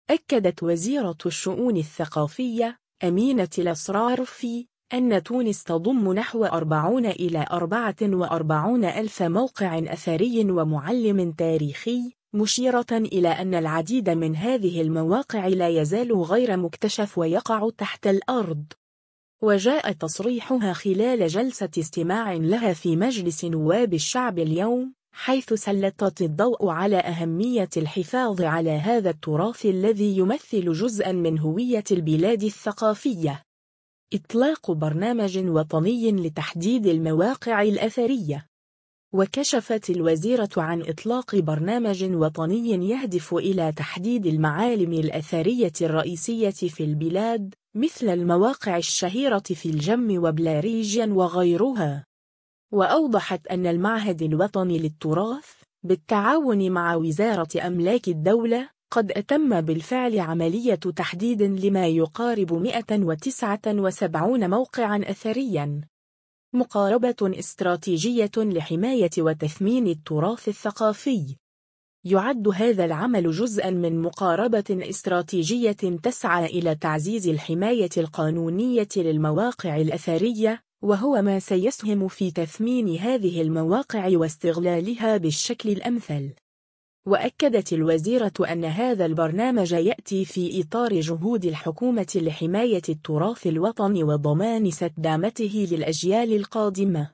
وجاء تصريحها خلال جلسة استماع لها في مجلس نواب الشعب اليوم، حيث سلطت الضوء على أهمية الحفاظ على هذا التراث الذي يمثل جزءاً من هوية البلاد الثقافية.